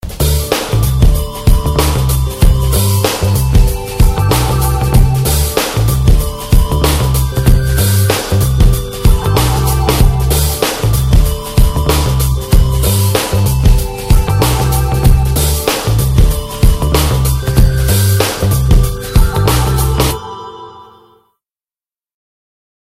Рингтоны » на смс